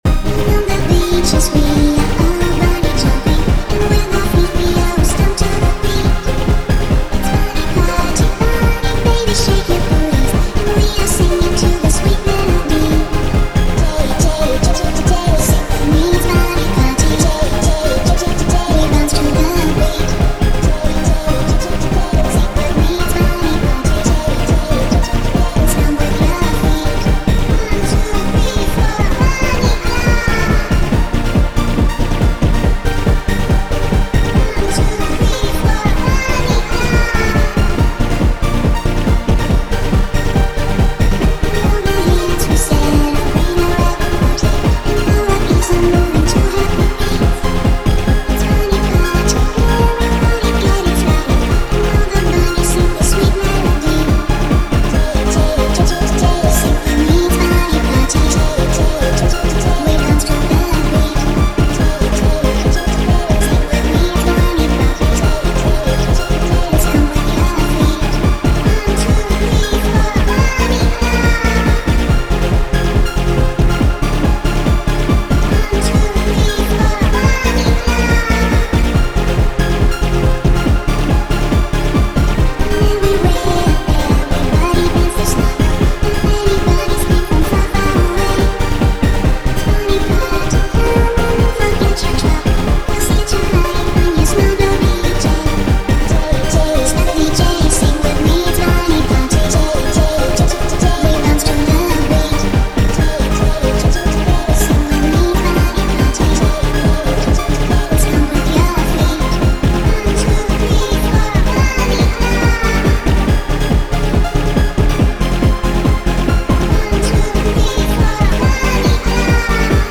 generative harmonic sequencer
Eurodance machine